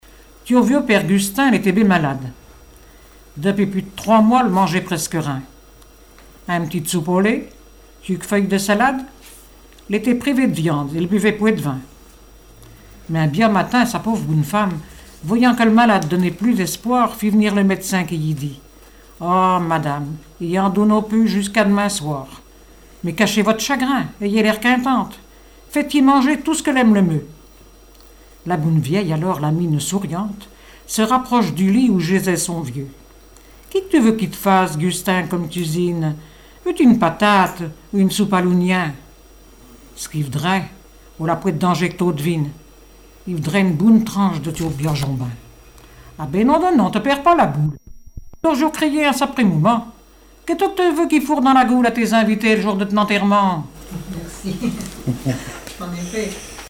Langue Patois local
Genre sketch
Catégorie Récit